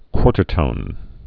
(kwôrtər-tōn)